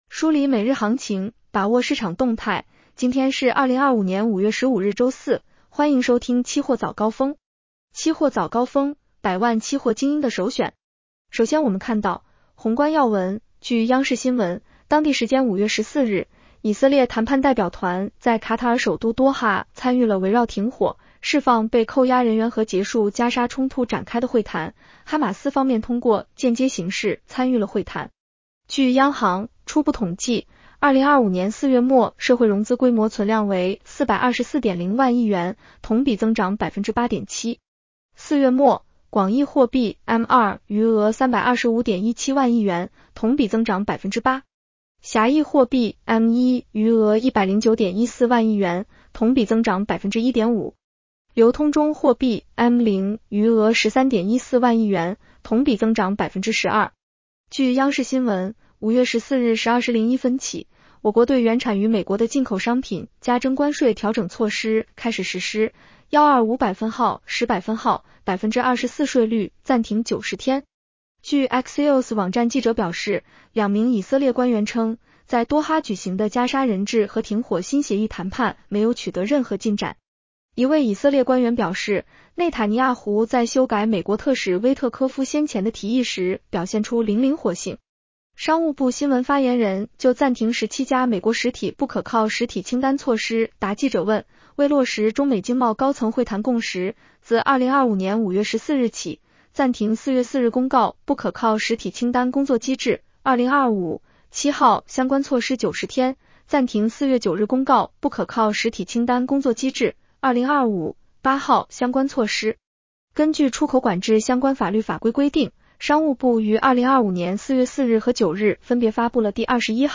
期货早高峰-音频版 女声普通话版 下载mp3 宏观要闻 1.